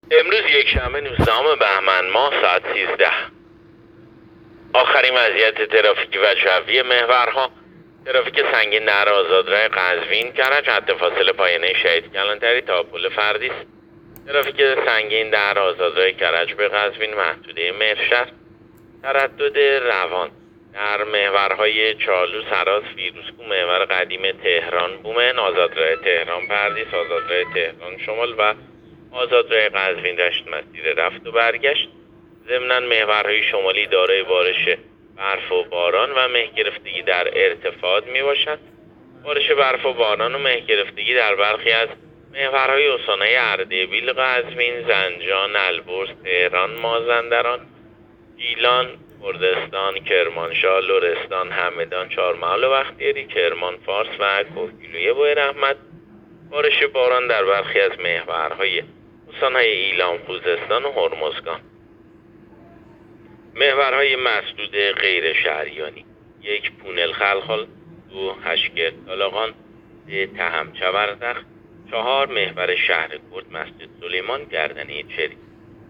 گزارش رادیو اینترنتی از آخرین وضعیت ترافیکی جاده‌ها ساعت ۱۳ نوزدهم بهمن؛